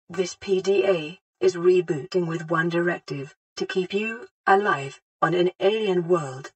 contribs)less grainy